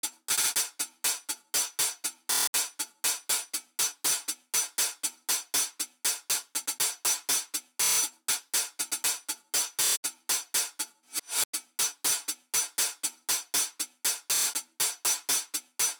Funk_d Up Hihat Loop (120BPM).wav